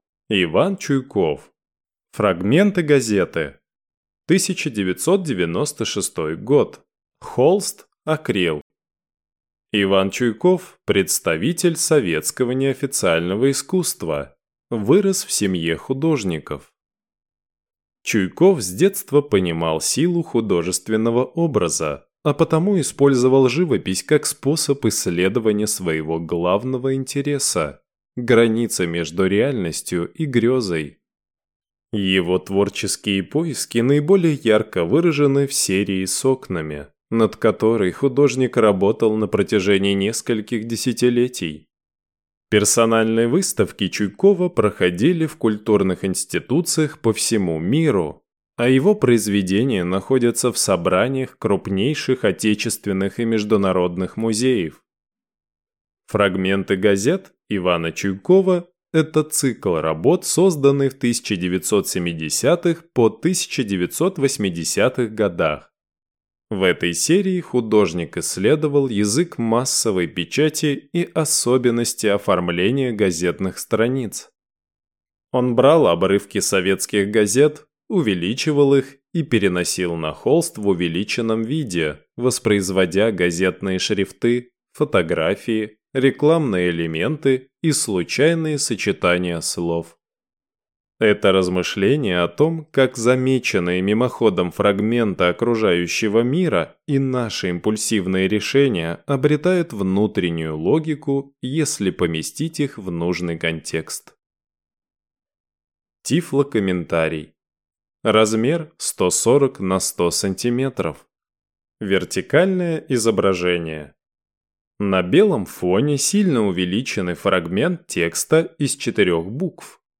Тифлокомментарий к картине Ивана Чуйкова "Фрагменты газеты" Тифлокомментарий к картине Игорья Макаревича и Елены Елагиной "Скульптура Пантерный мухомор с башней"